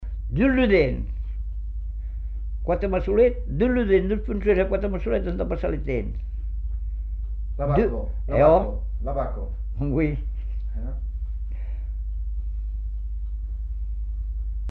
Aire culturelle : Savès
Effectif : 1
Type de voix : voix d'homme
Production du son : récité
Classification : devinette-énigme